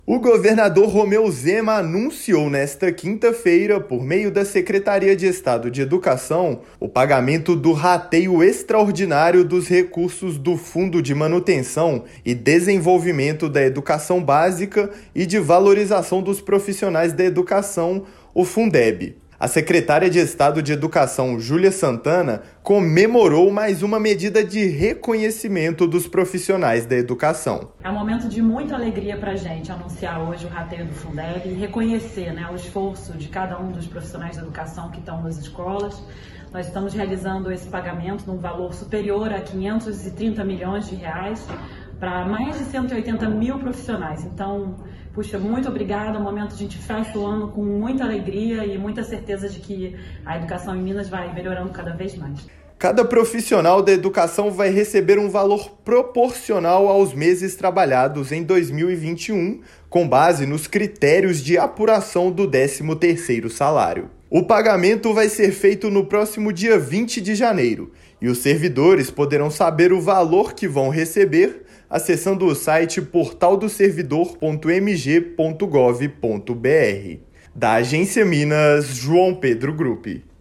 Parcela vai ser maior para os profissionais de unidades reconhecidas pelo Prêmio Escola Transformação. Ouça a matéria de rádio.